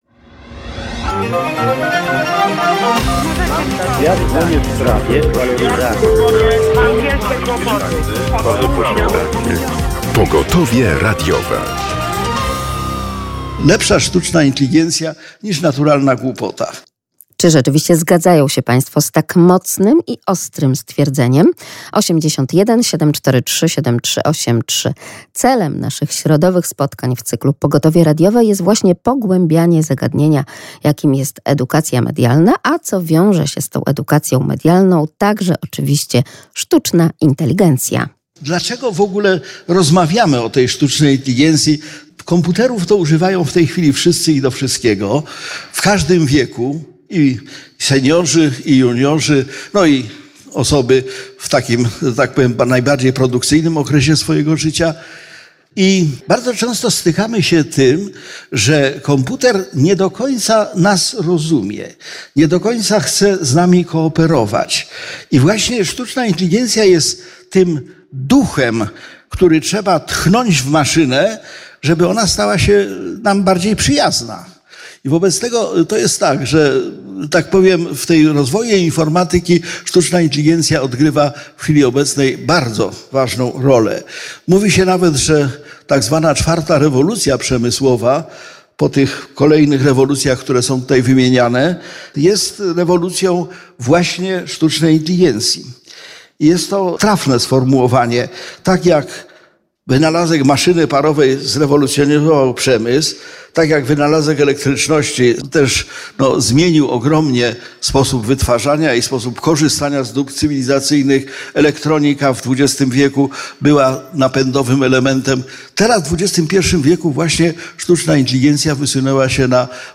Program będzie relacją reporterską ze szkolenia z zakresu edukacji medialnej, gdzie uczestnicy szkolenia poszerzają swoją wiedzę i umiejętności dotyczące języka mediów, sposobu komunikacji w mediach, tworzenia, przetwarzania i prezentowania treści, narzędzi nowych mediów, zasobów cyfrowych i ich wykorzystywania. Podejmiemy także temat poświęcony prawom autorskim i licencjom internetowym remiksującym zasoby cyfrowe.